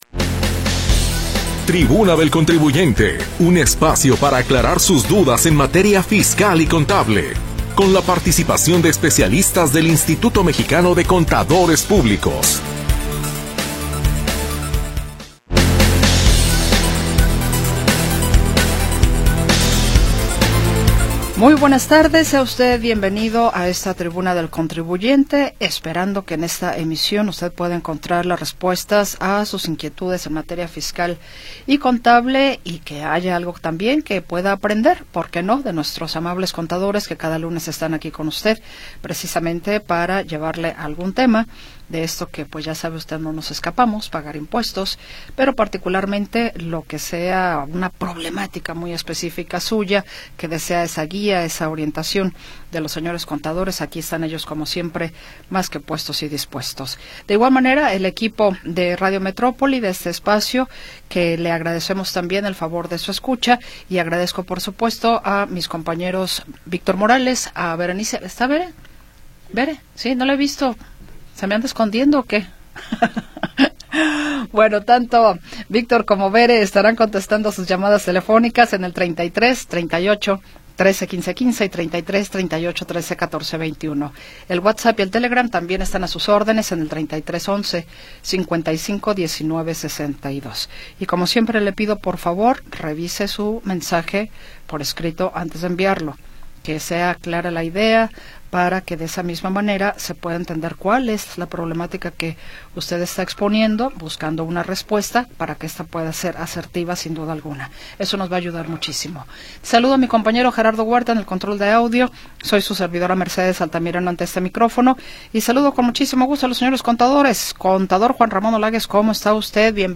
Orientación legal y contable con la participación de especialistas del Instituto Mexicano de Contadores.
Programa transmitido el 16 de Junio de 2025.